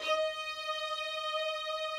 strings_063.wav